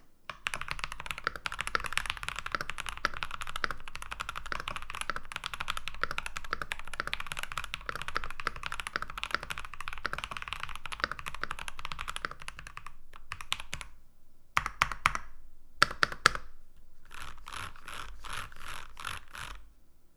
keychronc2pro8k-sound.wav